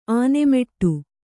♪ ānemeṭṭu